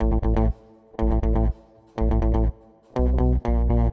ChromaticGuitarnBass.wav